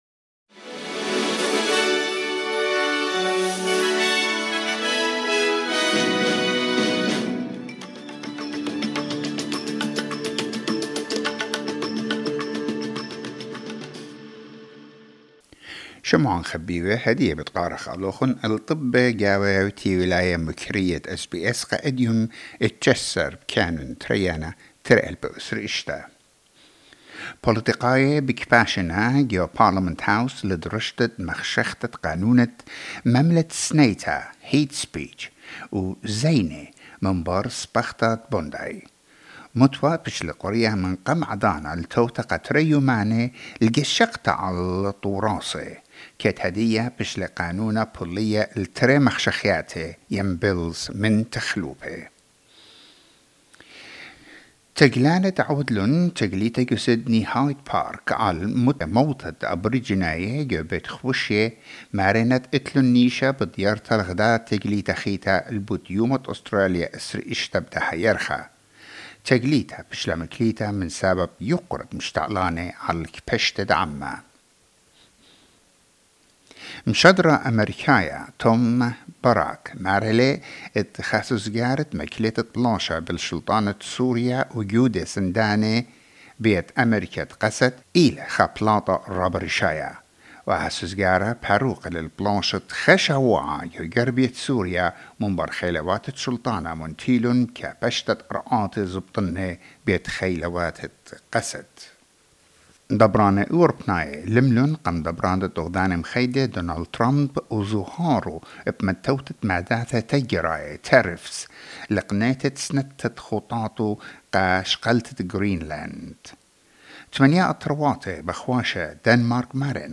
Newsflash 19 January 2026